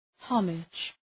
Προφορά
{‘hɒmıdʒ}